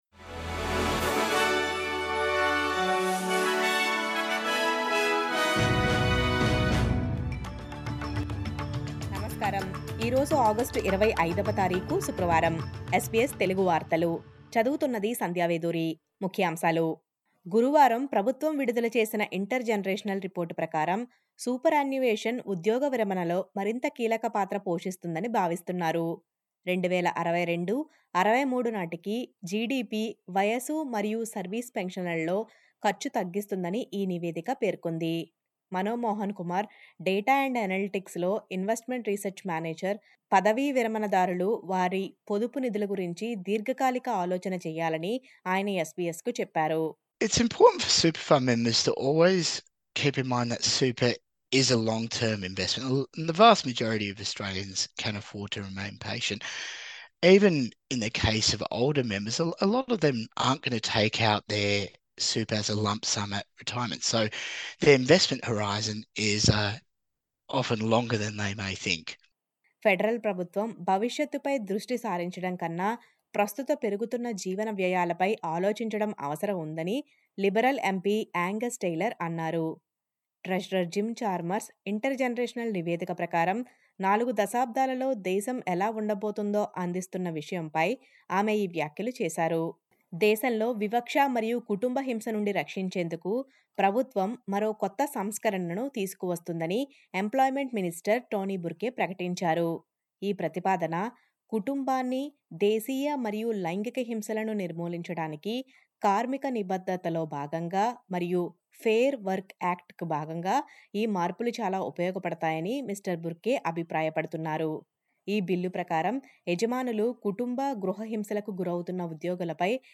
నమస్కారం, ఈ రోజు ఆగష్టు 25 వ తారీఖు శుక్రవారంg. SBS Telugu వార్తలు.